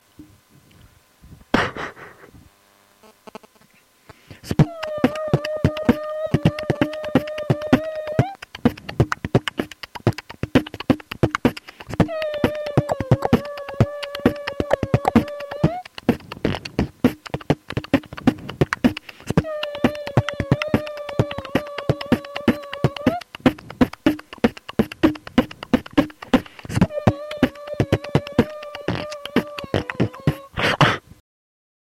b cl pf cl b cl b cl pf
BeatBox